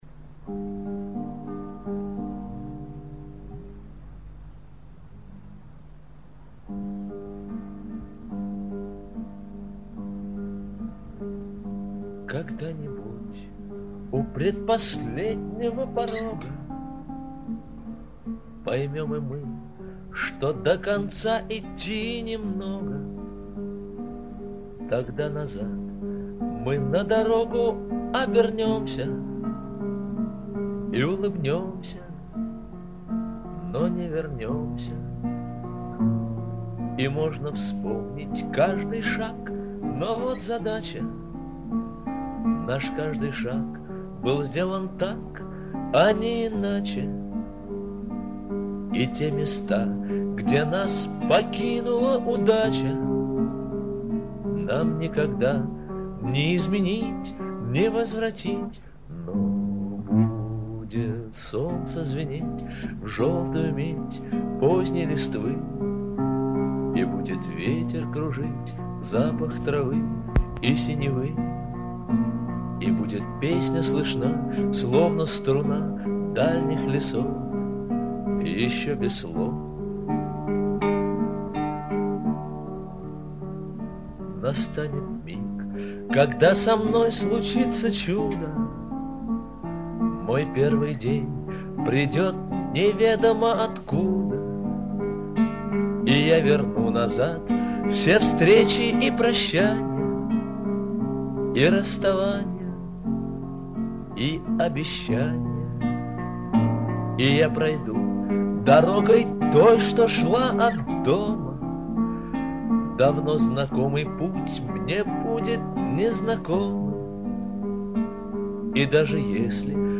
Не очень хорошая запись, другой не нашла.